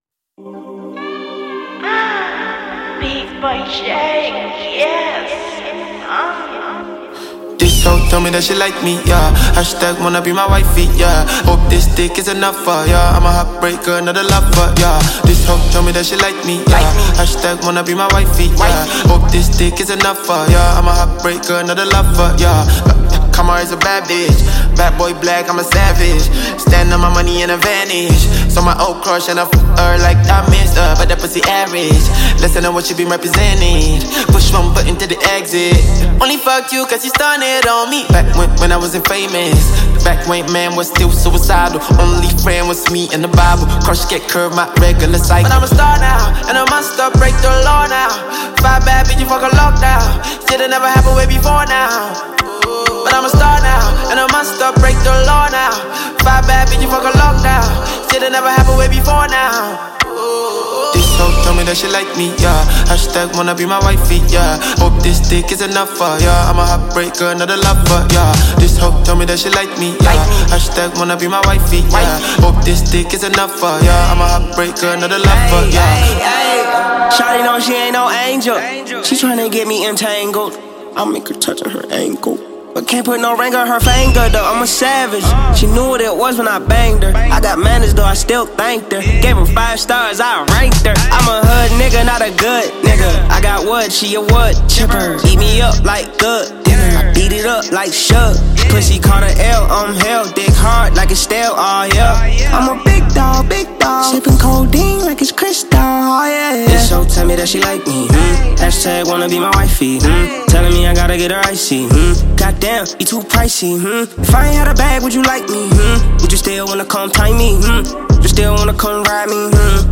MusicNigeria Music